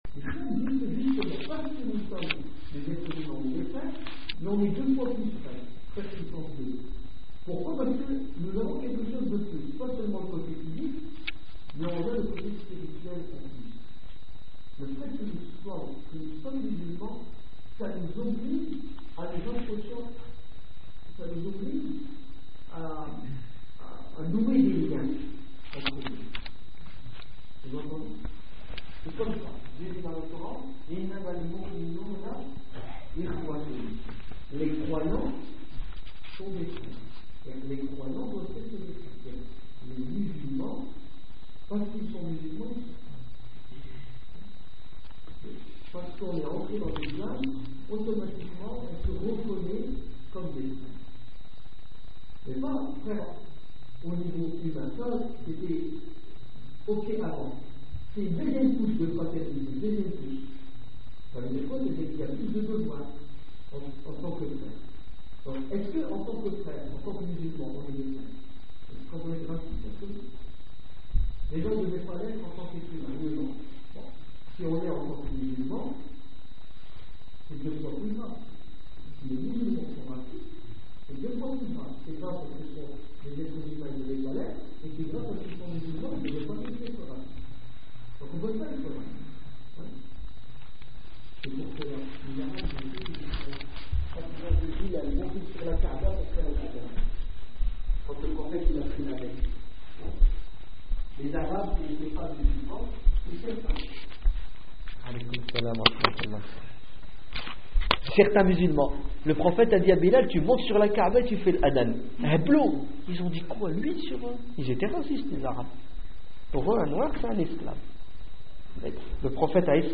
Conférence à la mosquée de Beuvrages du le 24 février 2006